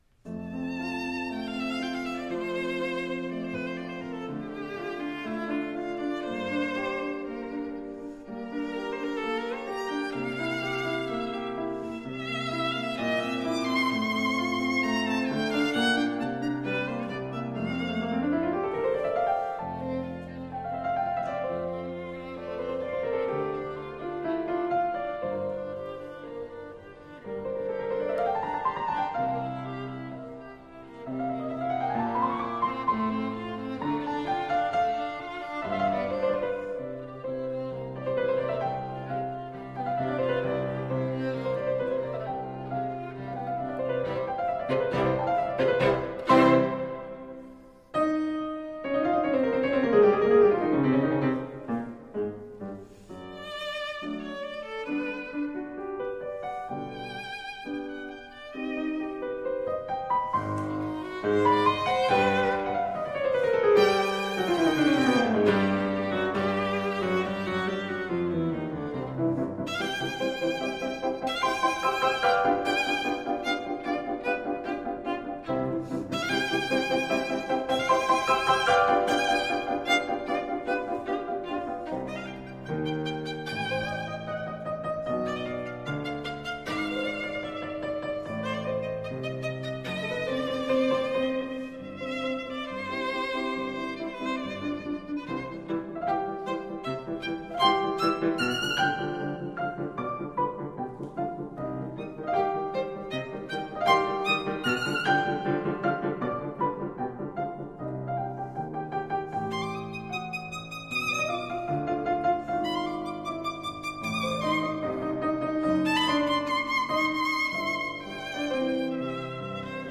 Style: Classical
Audio: Boston - Isabella Stewart Gardner Museum
violin